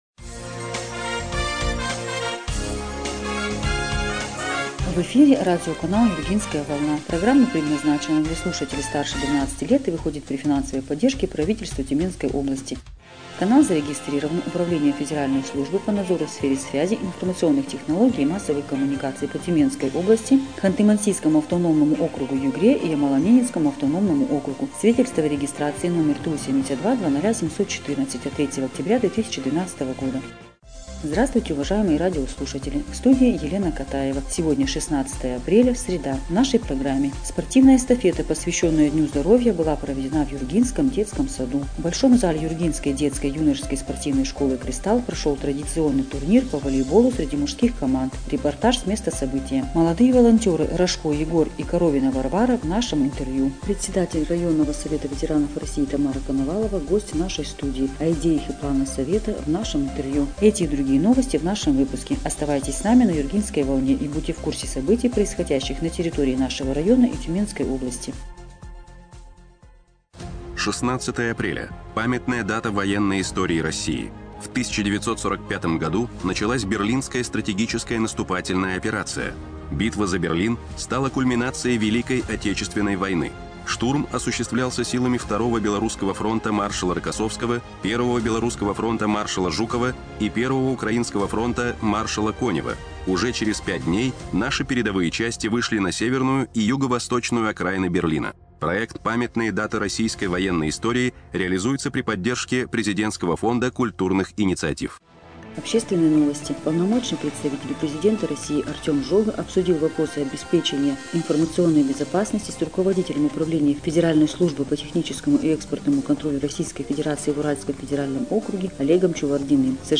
Эфир радиопрограммы "Юргинская волна" от 16 апреля 2025 года